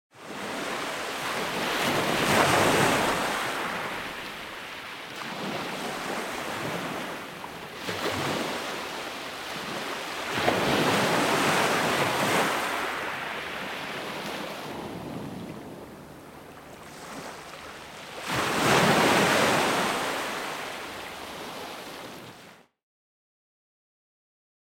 Download Relaxing Ocean Waves sound effect for free.
Relaxing Ocean Waves